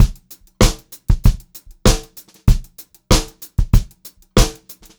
96POPBEAT3-R.wav